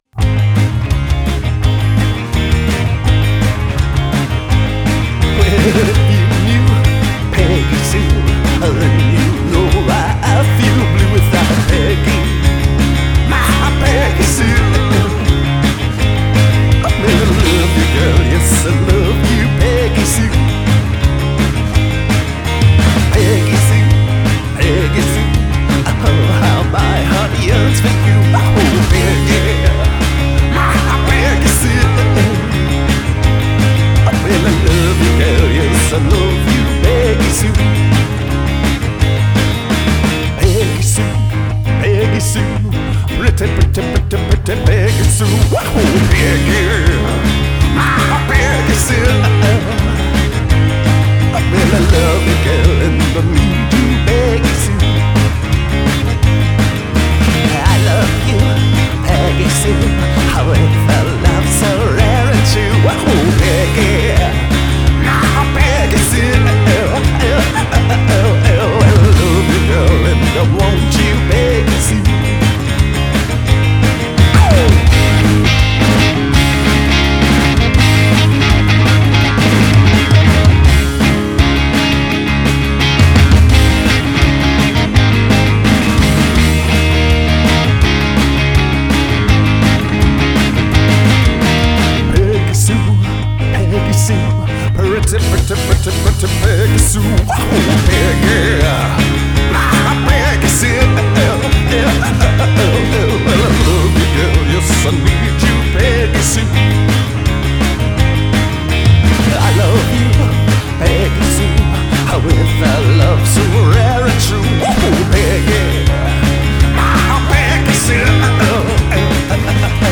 guitar + vocals
bass + vocals
drums